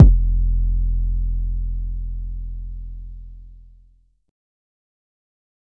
12kb - cashmoneyAP 808.wav